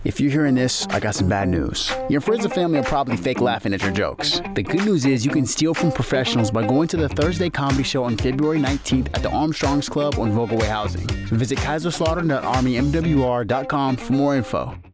Radio Spot - Thursday Night Comedy Show